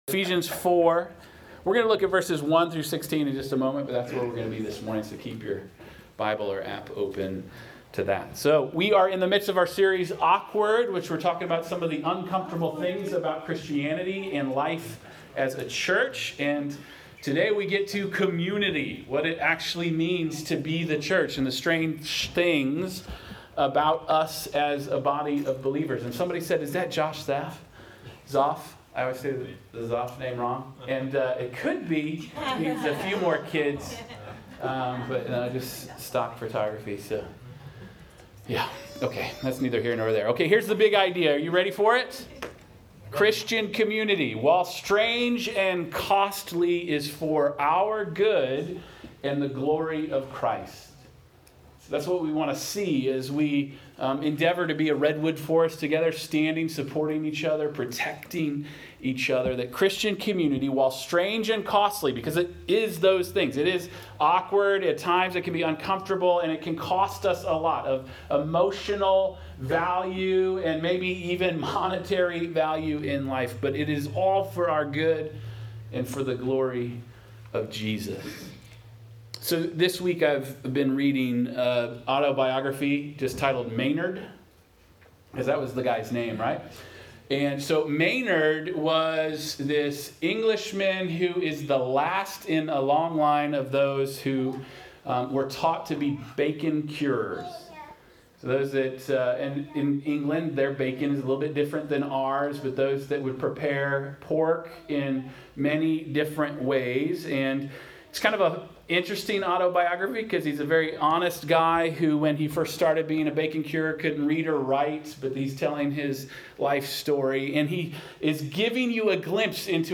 Christian community, while strange and costly, is for our good and the glory of Jesus. Sermon notes.